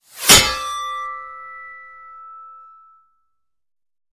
Звуки меча
Меч - Вот еще вариант